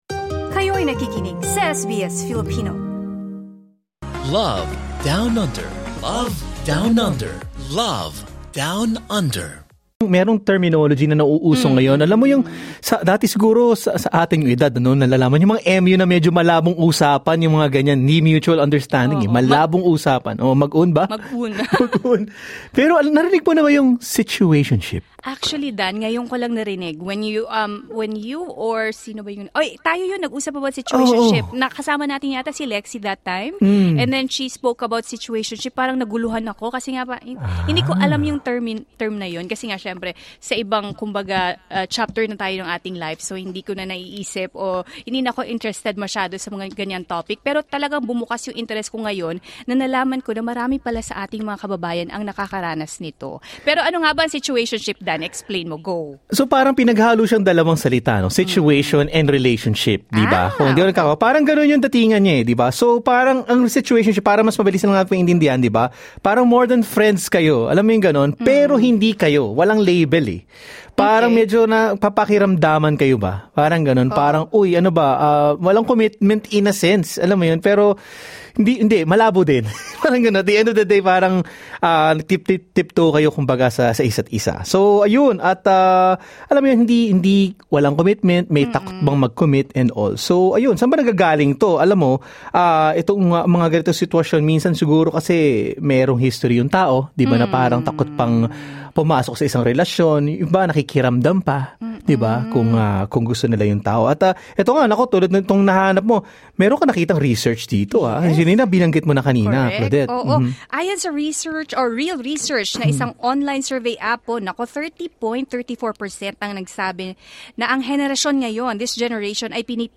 Two women share their experiences with both situationships and committed relationships, offering insights into what works for them in the world of modern dating.